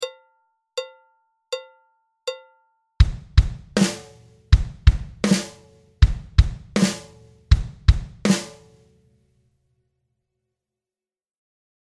• The notes on the snare drum are a drum rudiment called a Flam.
Stomp – Stomp – Clap
Bass – Bass – Snare (Flam)
Each measure repeats this rhythm. There are no extra fills or flourishes.